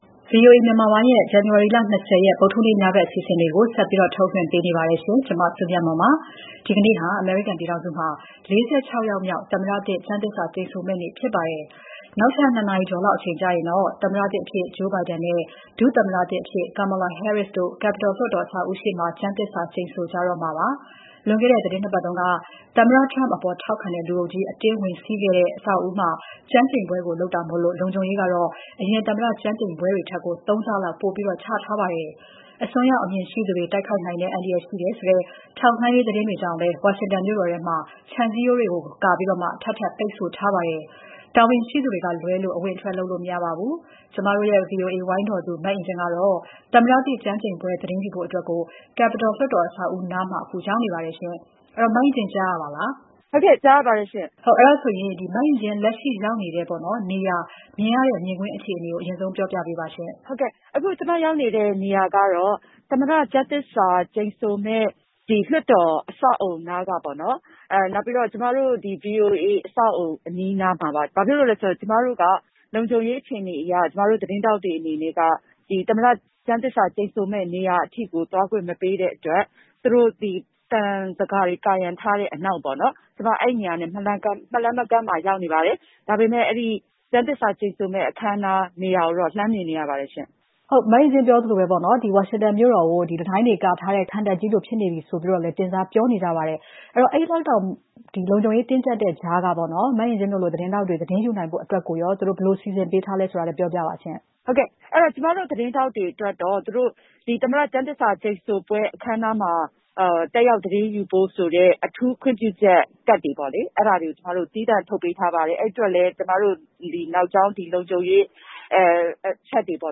သမ္မတသစ်ကျမ်းသစ္စာဆိုပွဲမတိုင်ခင် ဗွီအိုအေသတင်းထောက်နဲ့ မေးမြန်းမှု